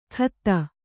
♪ khatta